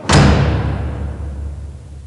shipslam.wav